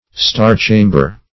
\Star"-cham`ber\(st[aum]r"ch[=a]m`b[~e]r), n. [So called (as